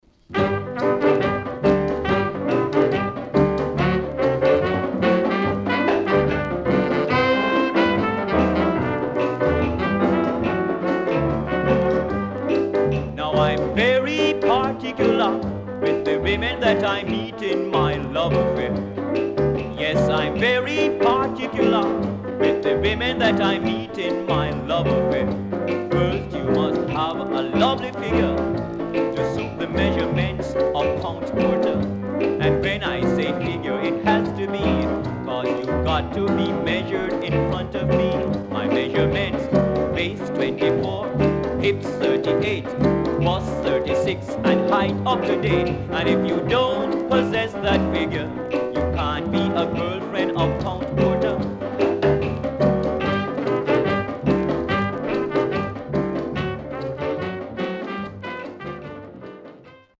BOLERO, MAMBO,CAYPSO等の良曲多数収録。
SIDE A:所々チリノイズ入ります。